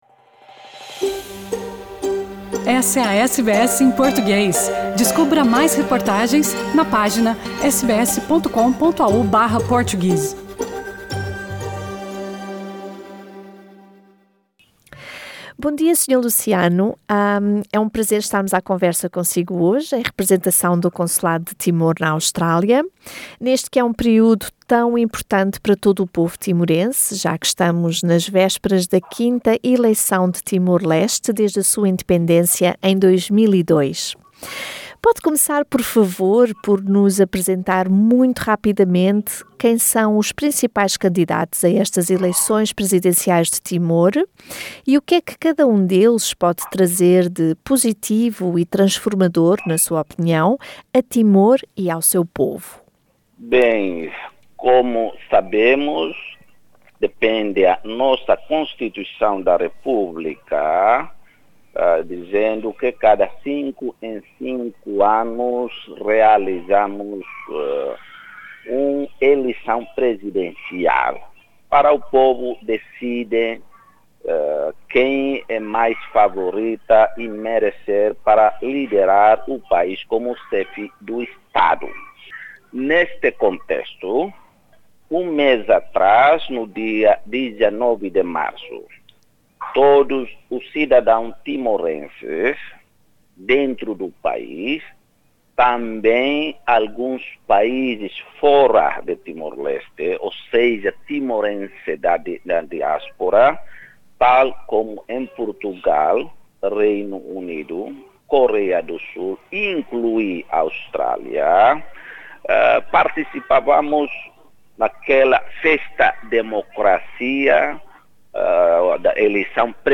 Os timorenses, em Timor e na diáspora, incluindo Austrália, vão às urnas hoje, terça-feira dia 19 de abril, naquela que é a segunda ronda das suas eleições presidenciais de 2022. Neste contexto, a SBS falou com o cônsul-geral de Timor-Leste para o estado de Nova Gales do Sul, Sr. Luciano Valentim da Conceição, que apelou a todos os timorenses que "façam o sacrifício" e não deixem de ir às urnas.